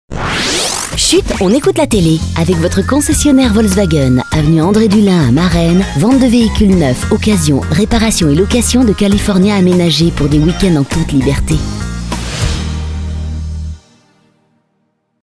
et voici le spot de notre partenaire